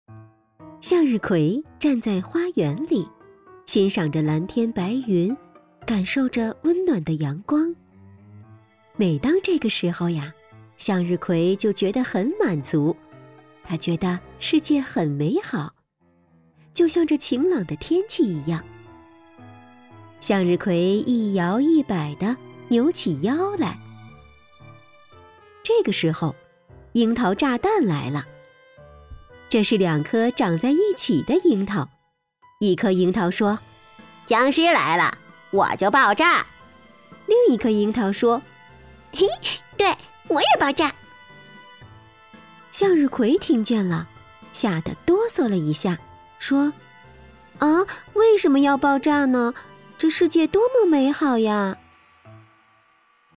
女国语330